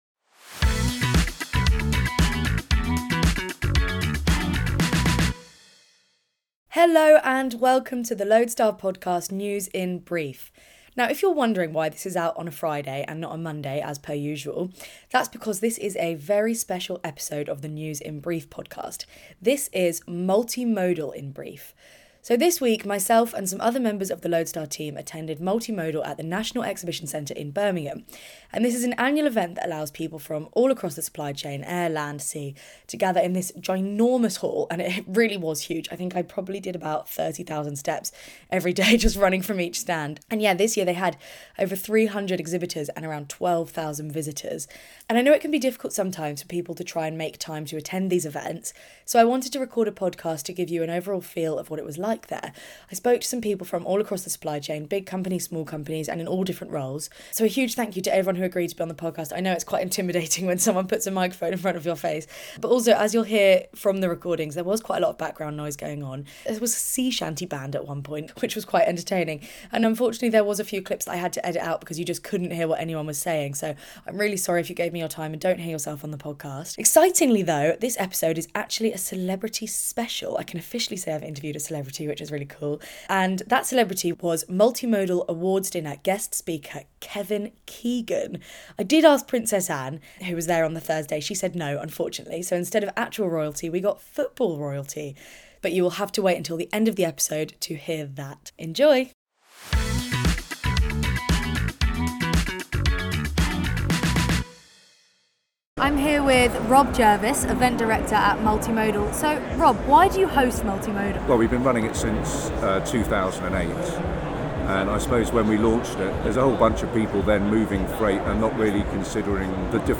spent her time at this week's Multimodal exhibition in Birmingham recording 'brief' interviews with visitors and exhibitors from all across the supply chain to hear why their company attended the event and what they thought of it.